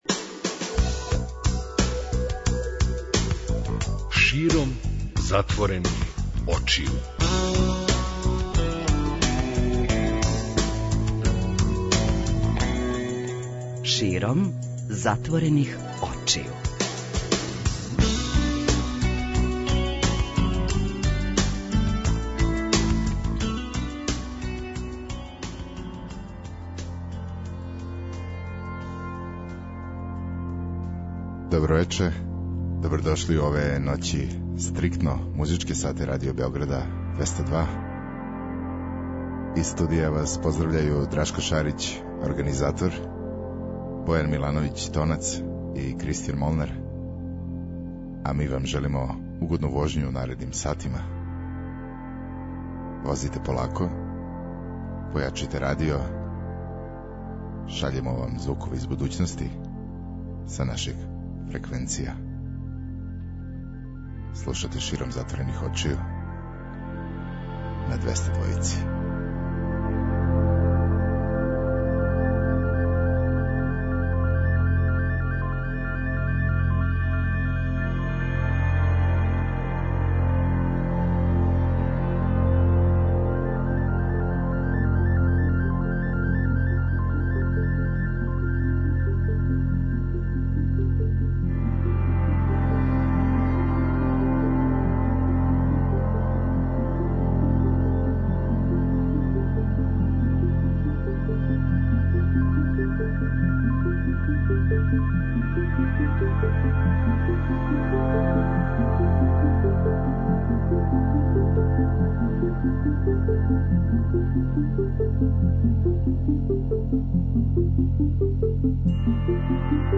Ноћни програм Београда 202